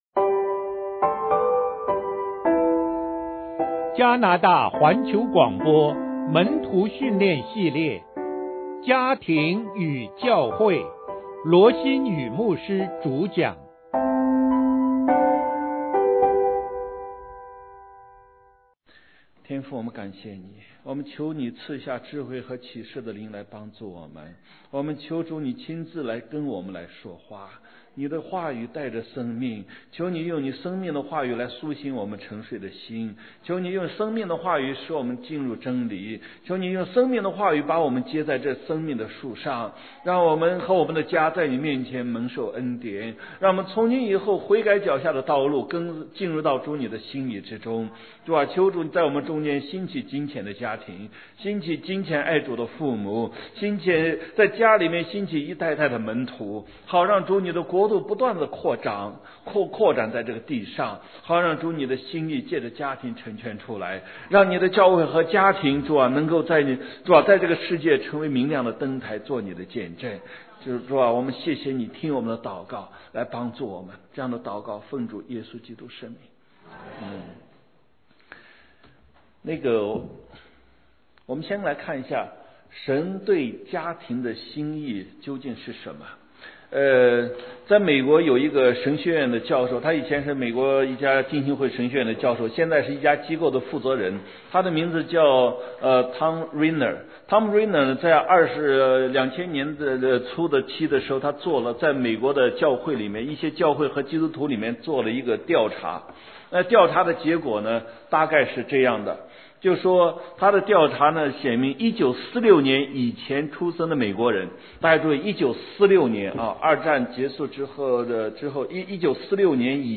講座錄音 家庭與教會 一 家庭與教會 二 家庭與教會 三 家庭與教會 四 家庭與教會 五 家庭與教會 六 講義 家庭與教會講義 門徒訓練系列 – 家庭與教會